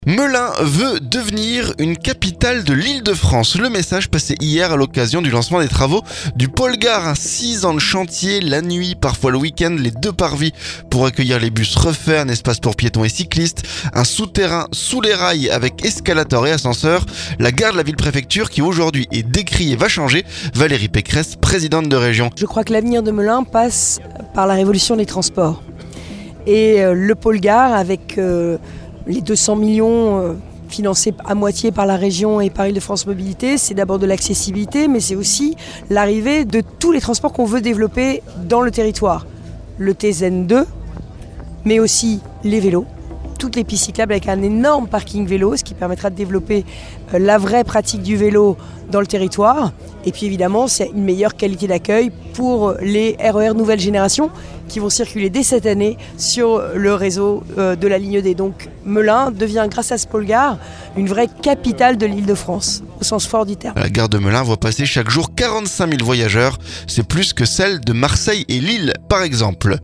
Valérie Pécresse, présidente de région.